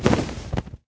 wings1.ogg